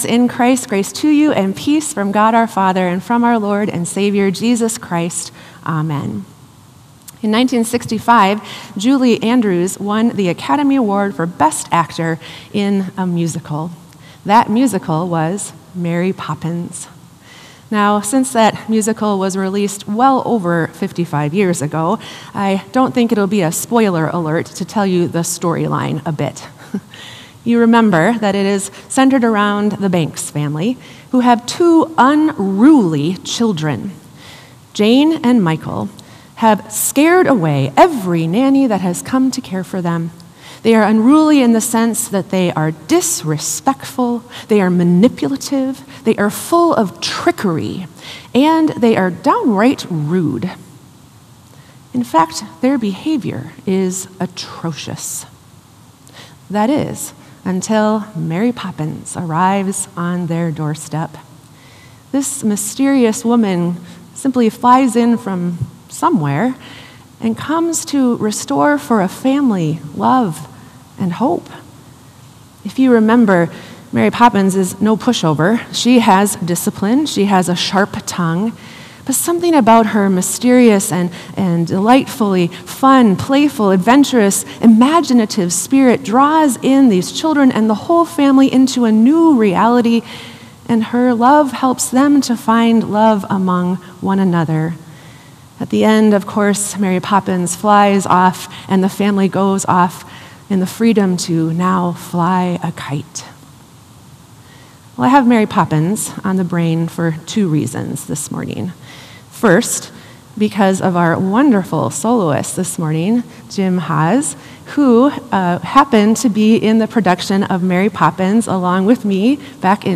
Sermon “The Tender Mercy of God” | Bethel Lutheran Church